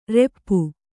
♪ reppu